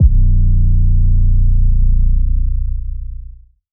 808 (Cannon).wav